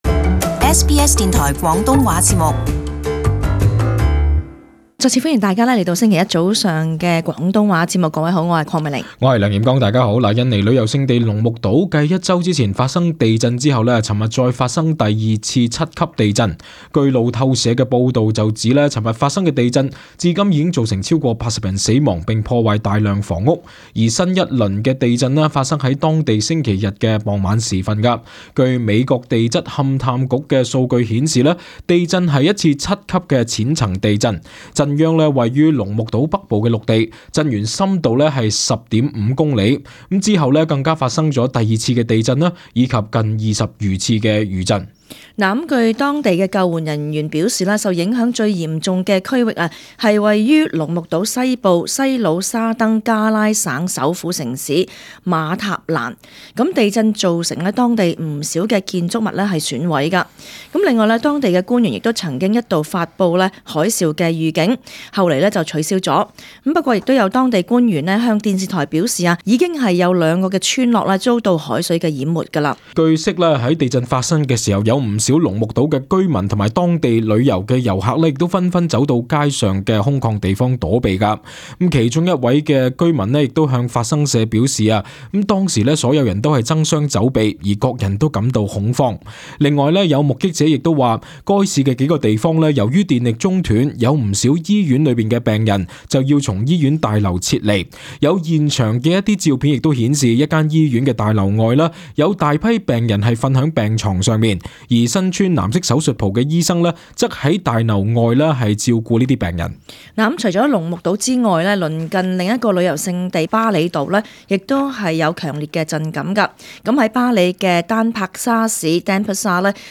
【時事報導】印尼再發生7級地震